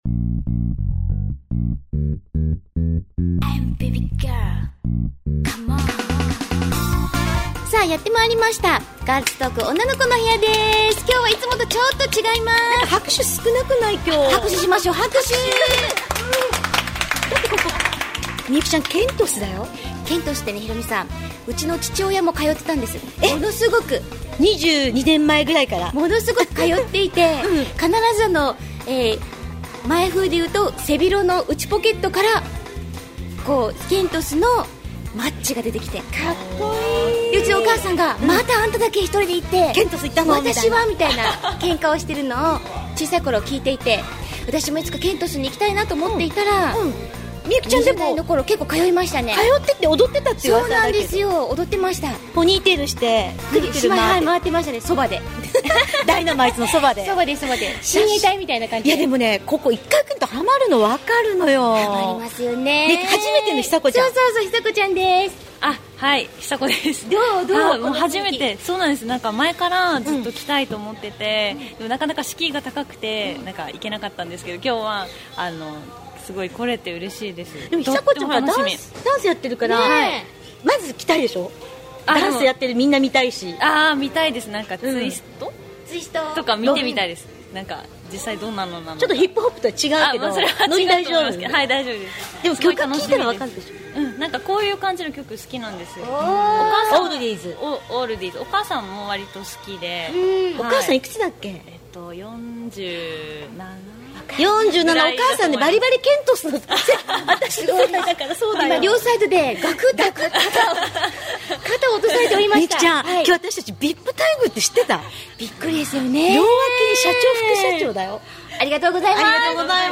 今回は那覇市・松山にあります老舗店舗 LIVE HOUSE Ｋｅｎｔｏｓでの収録です。